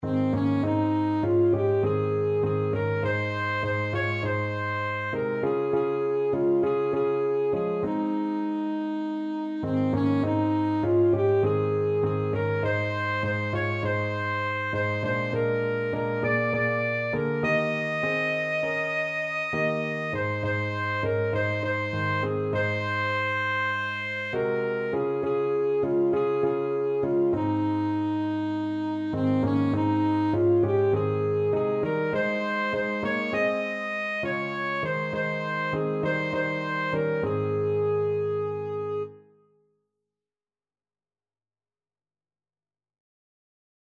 Christian
Alto Saxophone
C5-Eb6
4/4 (View more 4/4 Music)